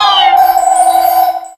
cry_CRESSELIA.ogg